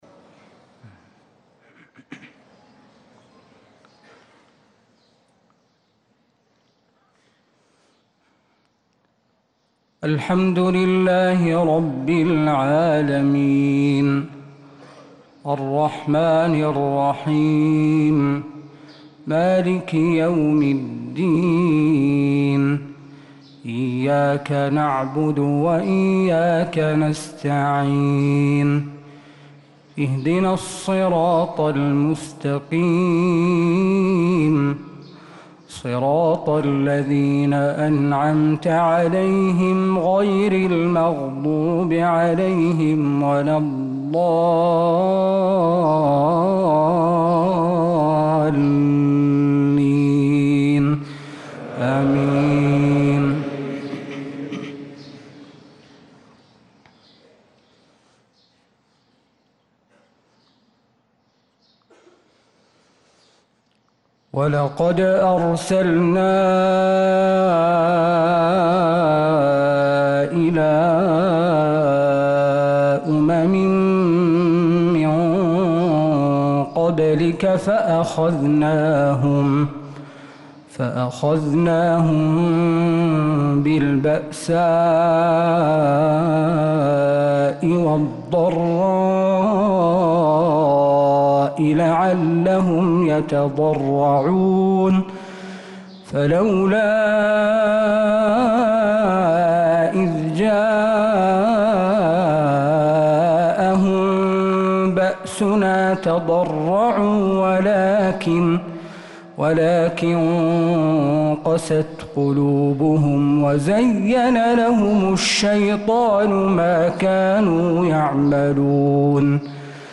صلاة الفجر